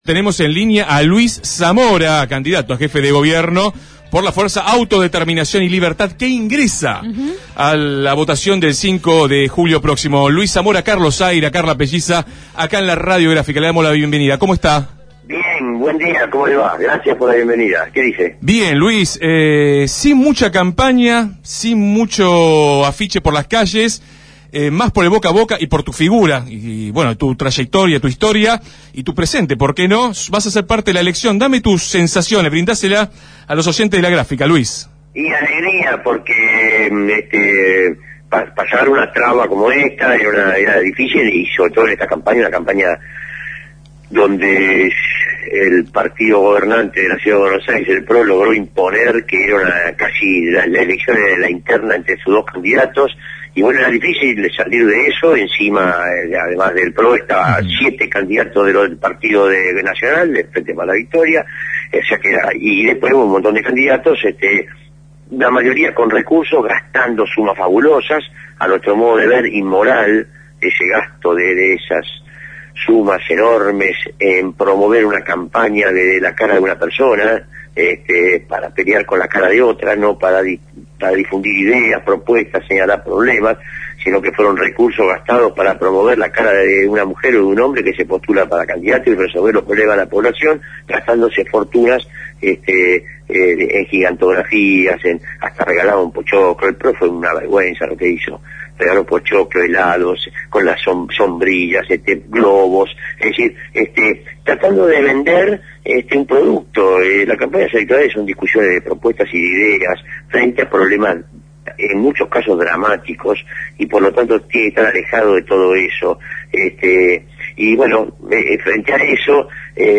El candidato a jefe de gobierno por Autodeterminación y Libertad Luis Zamora, que superó las PASO y competirá en las definitivas del 5 de julio, fue entrevistado en Desde el Barrio.